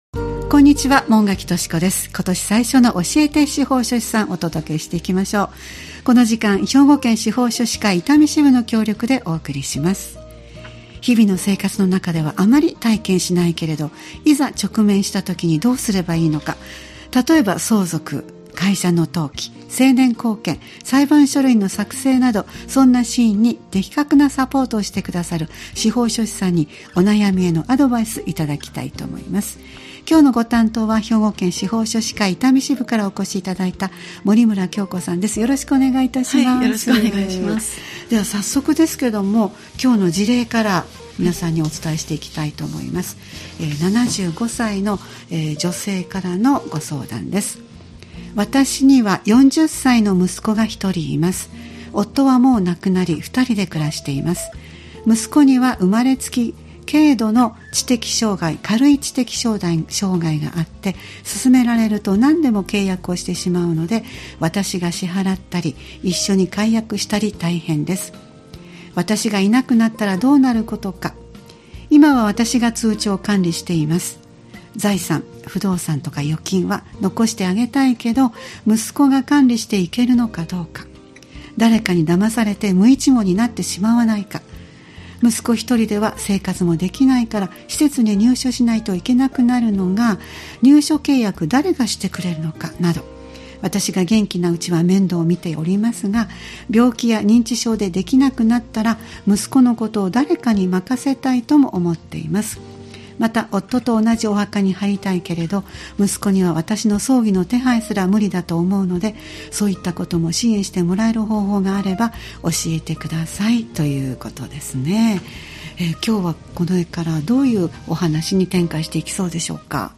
毎月第2水曜日は「教えて司法書士さん」を配信しています。毎回スタジオに司法書士の方をお迎えして、相続・登記・成年後見・裁判書類の作成などのアドバイスを頂いています。